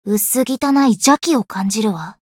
灵魂潮汐-神纳木弁天-互动-不耐烦的反馈1.ogg